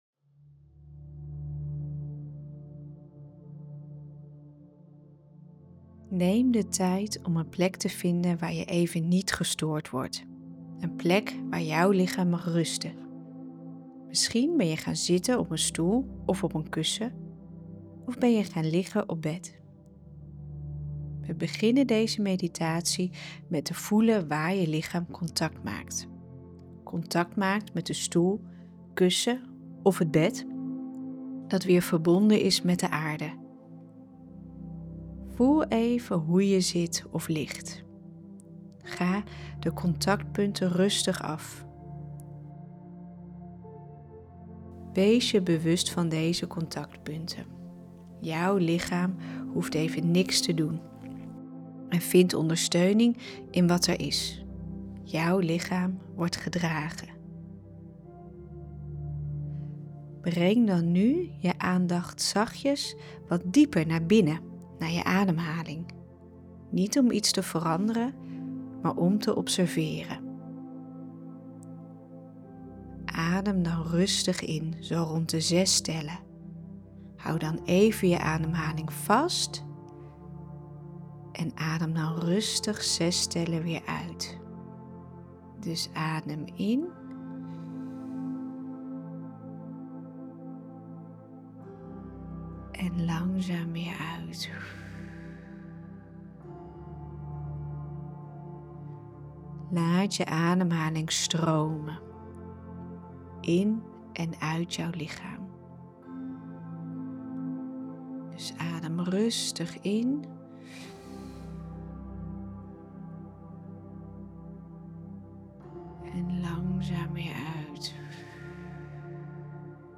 gratis meditatie.mp3
Want ik heb voor jou een gratis meditatie ontwikkeld en persoonlijk ingesproken. Een moment om even thuis te kunnen komen in jezelf en te voelen, écht te voelen dat je niet alleen bent.